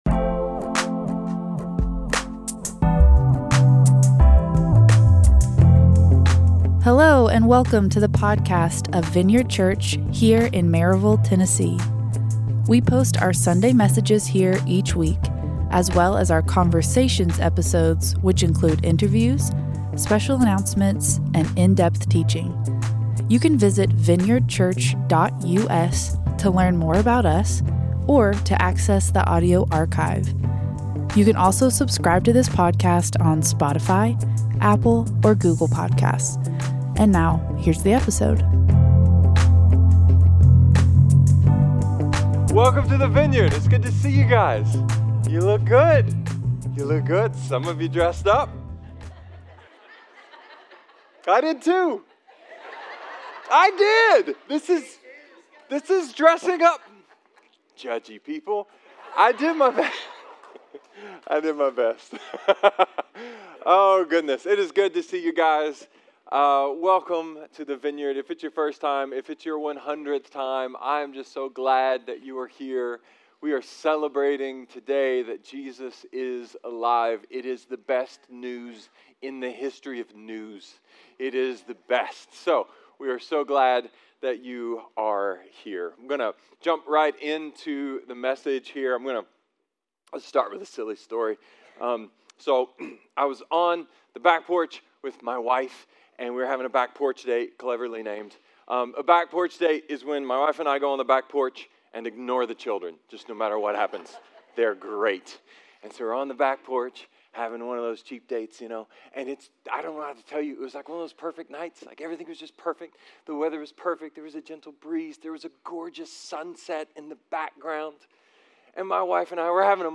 A sermon about faith, belief, resurrection and hope.